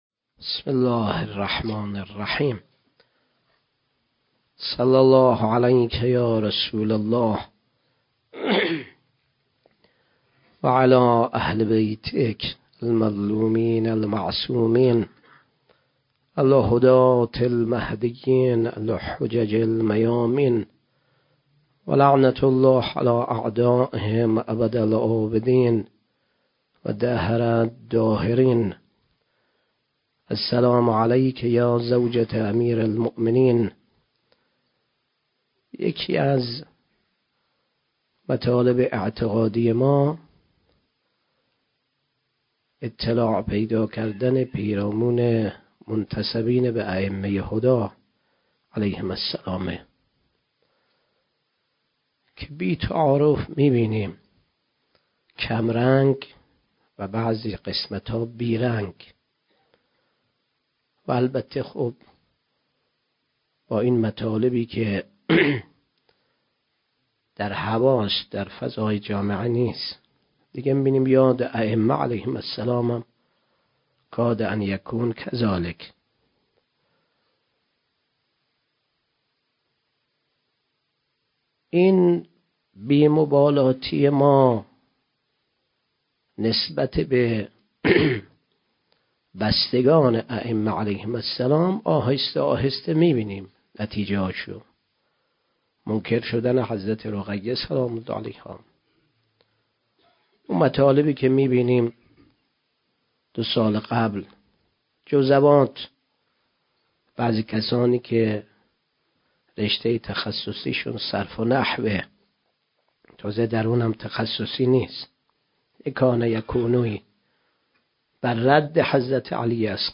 29 بهمن 97 - غمخانه بی بی شهربانو - سخنرانی